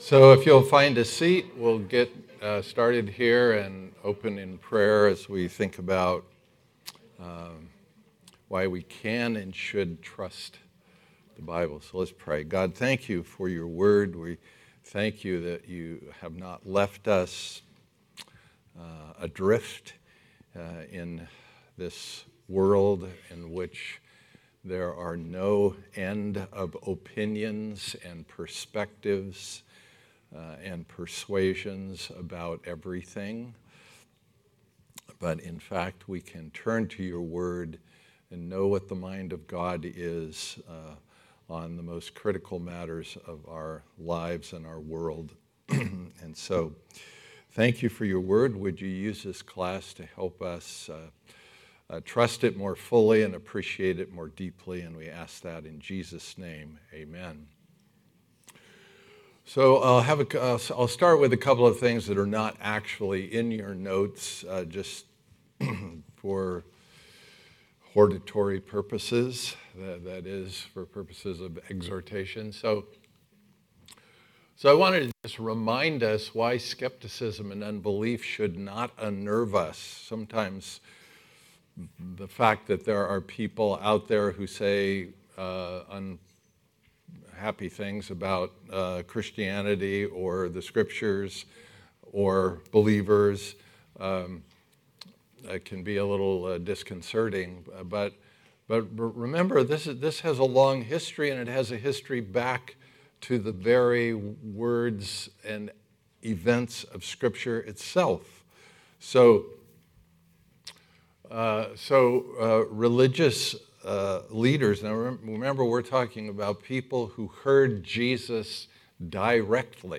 2023 Series: Trustworthy Type: Sunday School